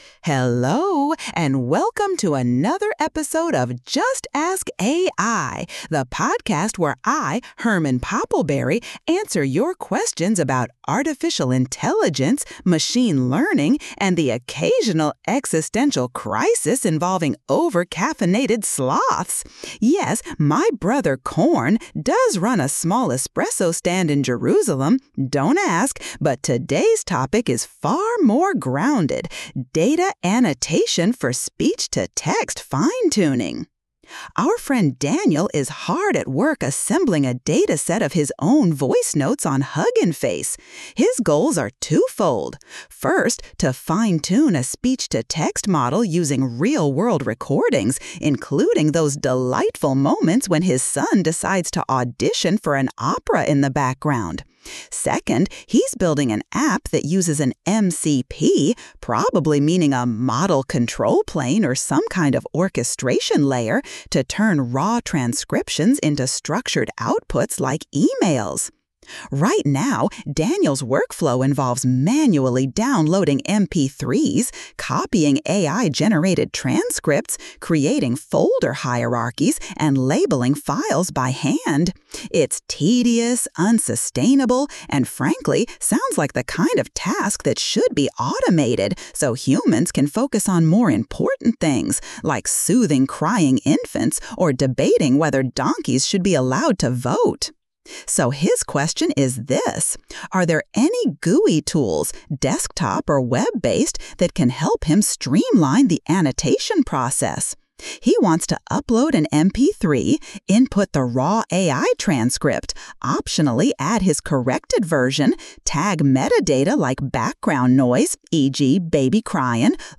AI-Generated Content: This podcast is created using AI personas.
This episode was generated with AI assistance. Hosts Herman and Corn are AI personalities.